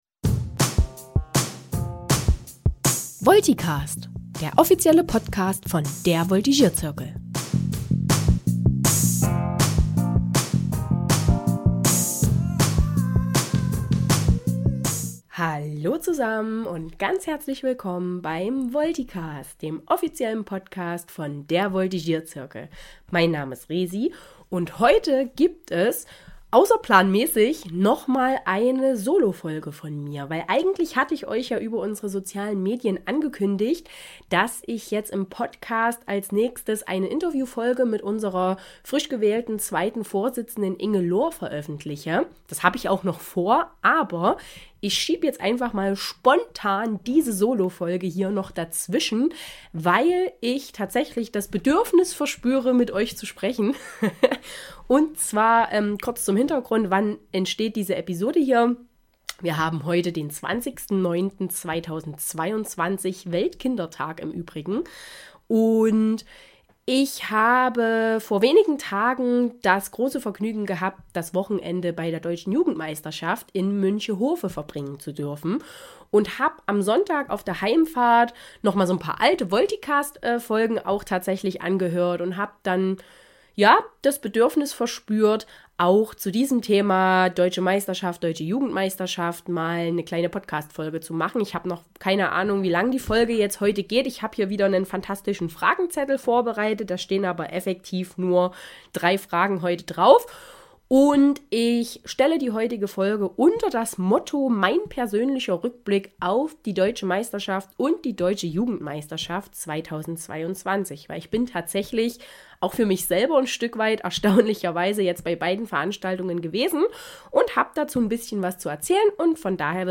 Diese Folge ist eine „außerplanmäßige“ und eher spontan entstandene Solofolge.